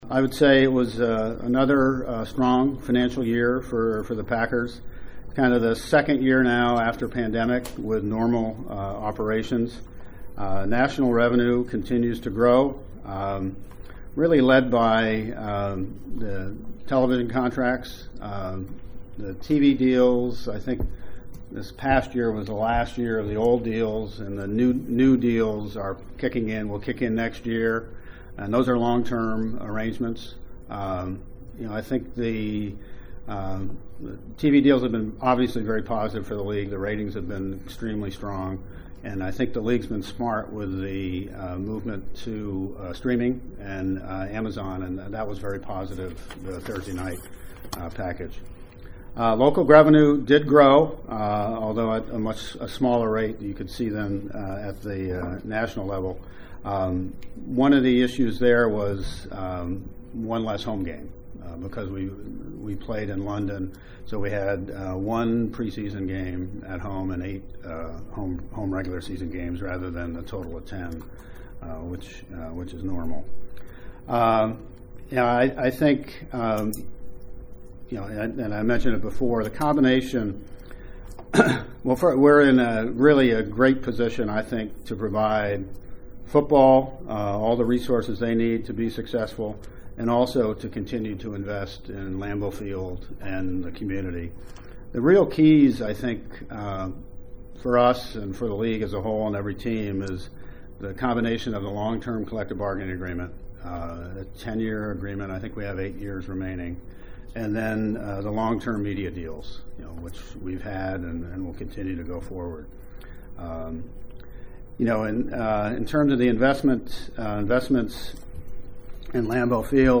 In the formal presentation to reporters, Murphy explained the variety of factors that led to the bottom lines, the only financial figures you’ll ever see from an NFL franchise.